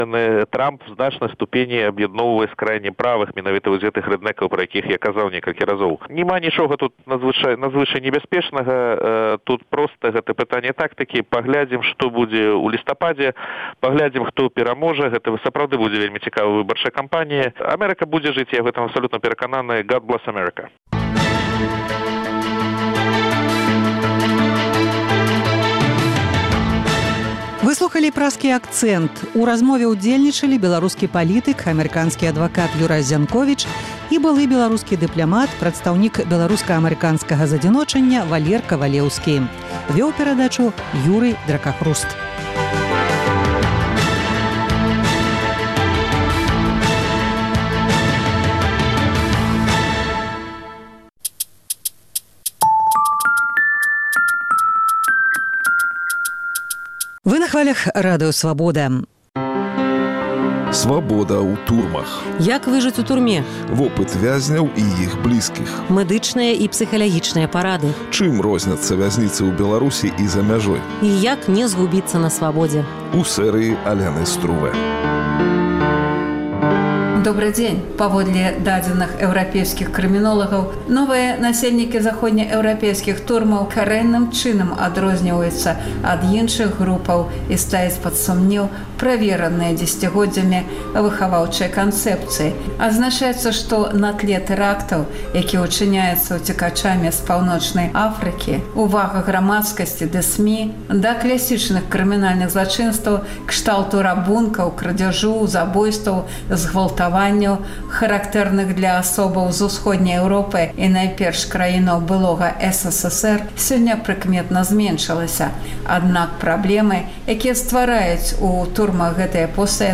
У новай перадачы "Свабода ў турмах" былыя ўцекачы зь Беларусі, беларусы, якія сядзелі ў эўрапейскіх турмах, заходнія адмыслоўцы-крымінолягі разважаюць пра крымінальнікаў, якія трапляюць у ЭЗ пад выглядам палітычных ахвяраў